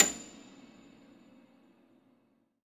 53a-pno29-A6.aif